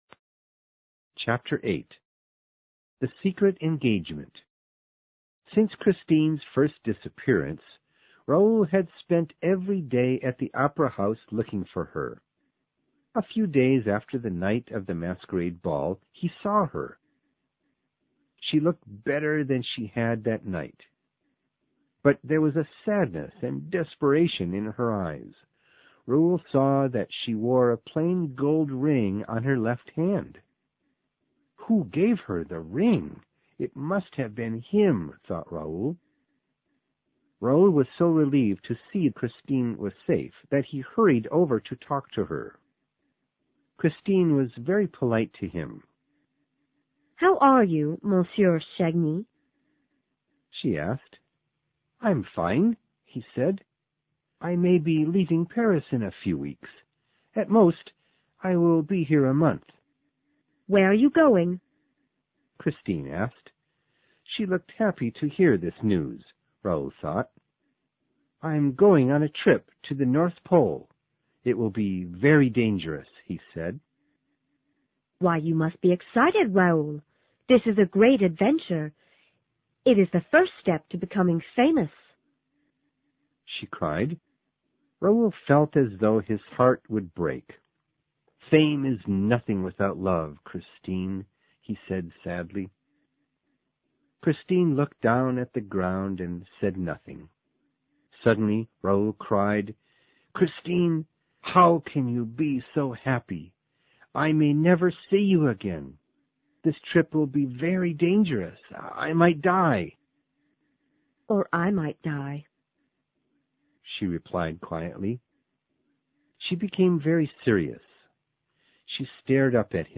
有声名著之歌剧魅影07 听力文件下载—在线英语听力室